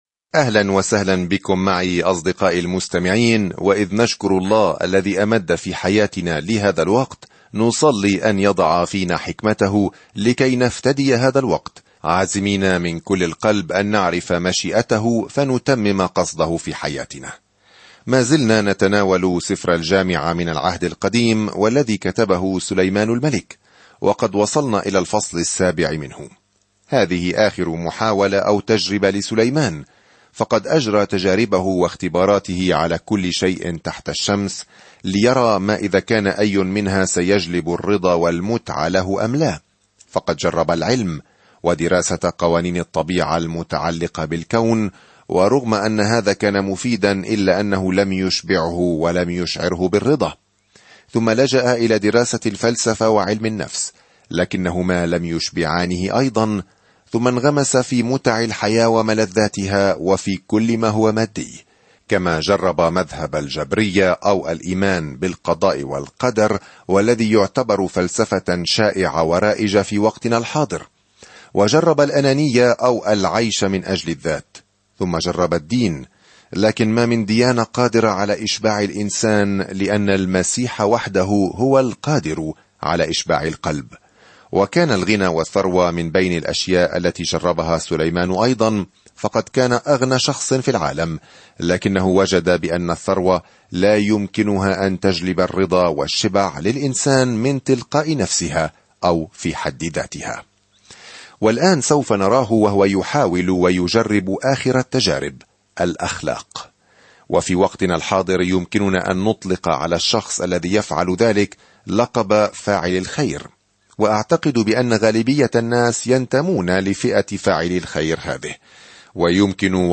الكلمة اَلْجَامِعَةِ 7 اَلْجَامِعَةِ 8 يوم 7 ابدأ هذه الخطة يوم 9 عن هذه الخطة سفر الجامعة هو سيرة ذاتية درامية لحياة سليمان عندما كان يحاول أن يكون سعيدًا بدون الله. السفر اليومي من خلال الجامعة تستمع إلى الدراسة الصوتية وتقرأ آيات مختارة من كلمة الله.